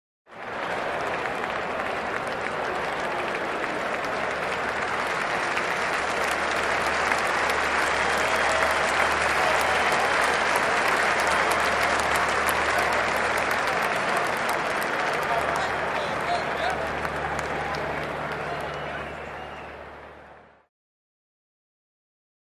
Applause | Sneak On The Lot
Stadium Crowd: Congratulatory Applause; Player Retiring; Large Crowd Walla / Controlled Applause, Distant Perspective.